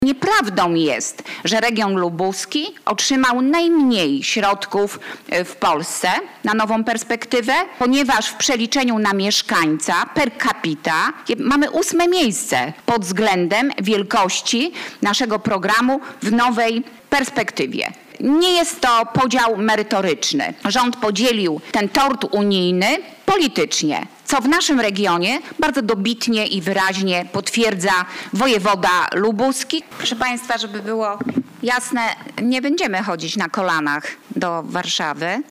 '- Nie będziemy chodzić na kolanach do Warszawy po pieniądze unijne – mówiła dziś na konferencji prasowej Marszałek Elżbieta Polak.